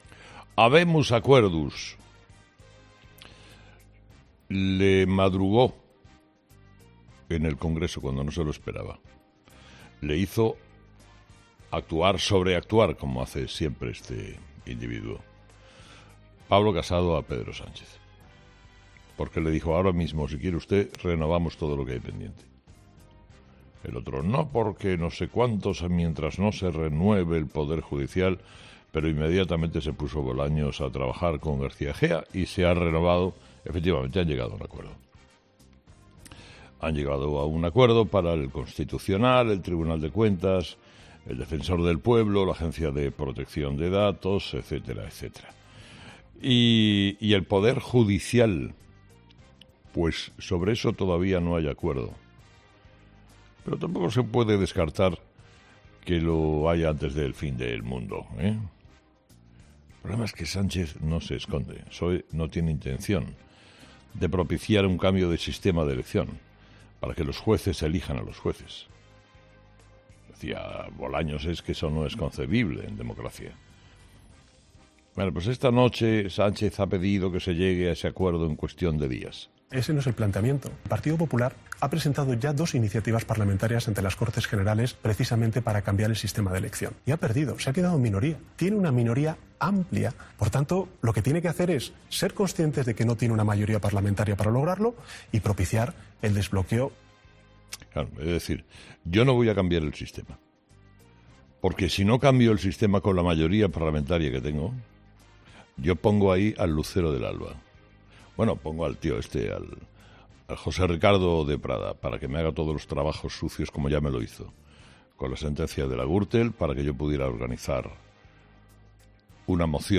Carlos Herrera, director y presentador de 'Herrera en COPE', ha comenzado el programa de este viernes analizando las principales claves de la jornada, que pasan, entre otros asuntos, por el acuerdo alcanzado entre Moncloa y el Partido Popular para renovar algunas instituciones, aunque siguen sin ponerse de acuerdo para poner punto y final al bloqueo del Consejo General del Poder Judicial: "El PSOE no tiene intención de cambiar el sistema de elección, para que los jueces elijan a los jueces.